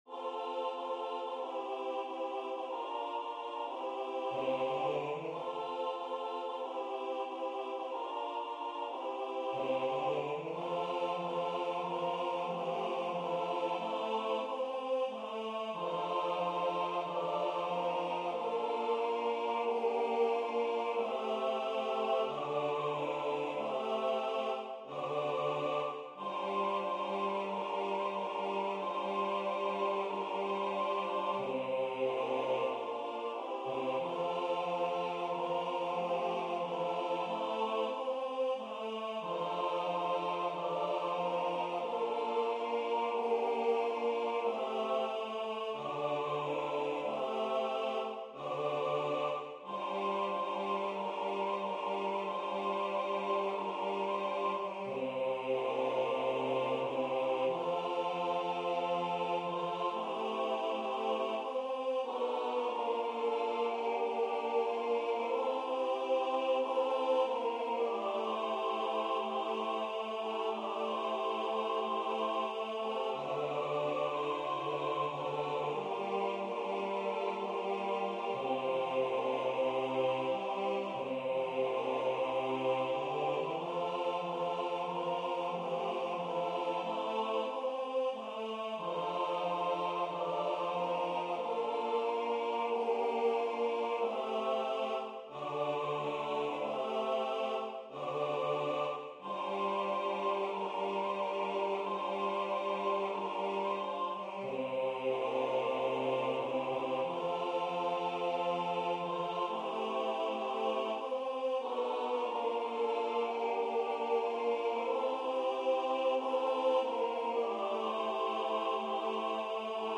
Chormusik
Genre Chor